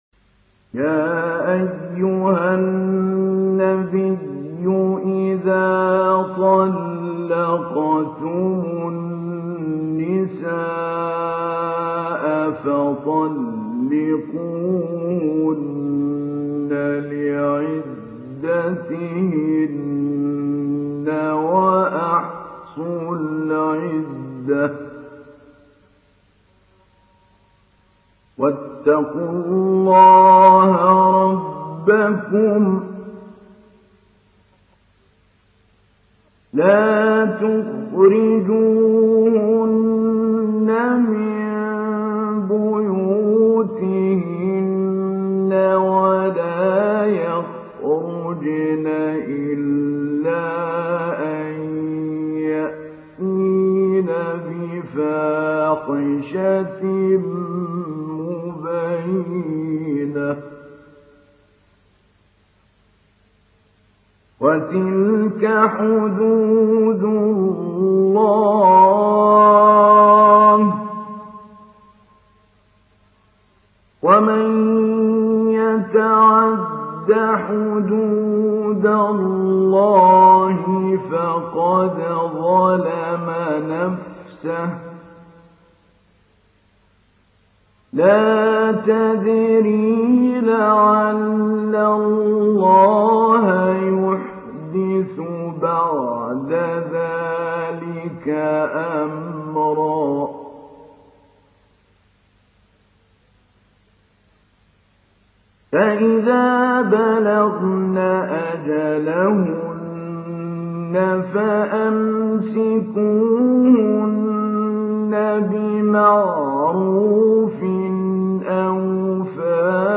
Sourate At-Talaq MP3 à la voix de Mahmoud Ali Albanna Mujawwad par la narration Hafs
Une récitation touchante et belle des versets coraniques par la narration Hafs An Asim.